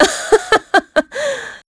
Ripine-Vox_Happy3.wav